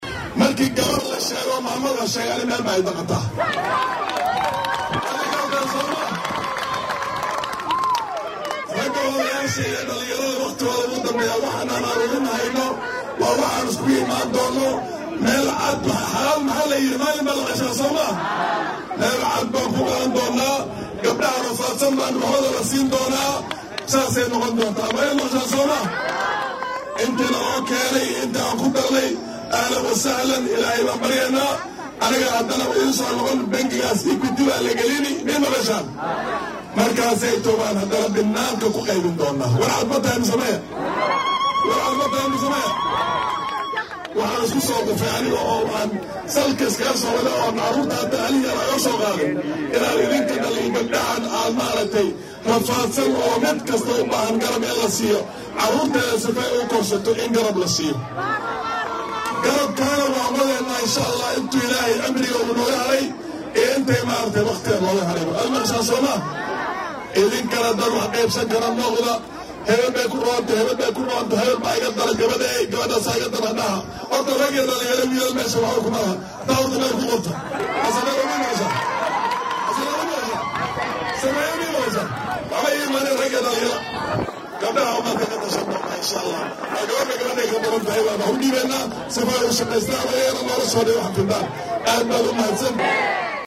Xildhibaanka laga soo doortey deegaan barlamaaneedka Dhadhaab ee ismaamulka Gaarisa ayaa shacabka deegaankaas kula dardaarmay in ay muhiim tahay in dumarka garab la siiyo si ay ganacsiyo Yar yar u sameystaan. Waxaa uu hadaladan jeediyay xilli uu ka qayb galay munnasabada awoodsiinta haweenka oo ka dhacday deegaanka Dhadhaab taasoo uu marti ku ahaa madaxweyne ku xigeenka dalka Prof. Abraham Kithure Kindiki.